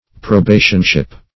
Probationship \Pro*ba"tion*ship\, n. A state of probation.